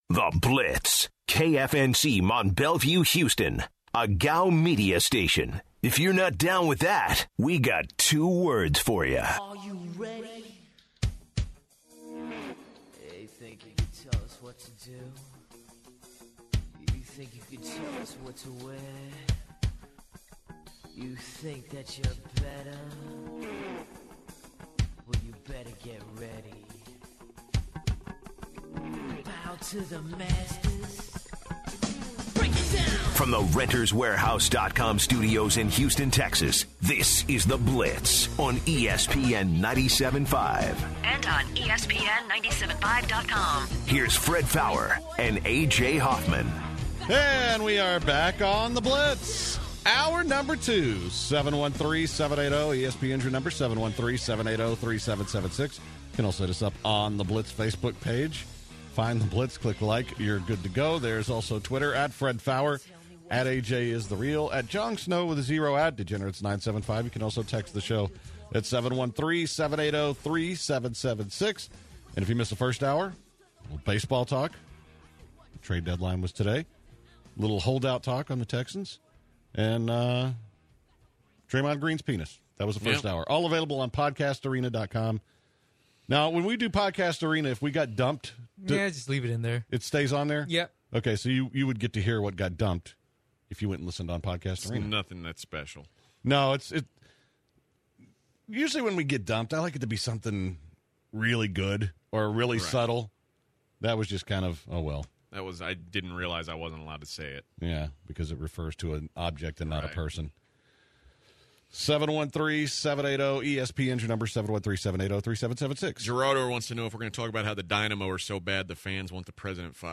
The blitzer's call the show to talk about UFC 201, Pacquiao, and will he be ready to fight again?